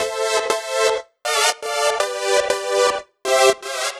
Index of /musicradar/french-house-chillout-samples/120bpm
FHC_Pad B_120-A.wav